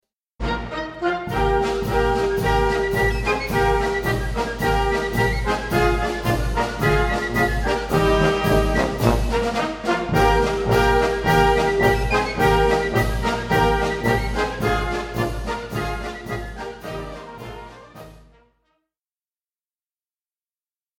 Gattung: Marsch
Besetzung: Blasorchester
Einer der bekanntesten österreichischen Militärmärsche.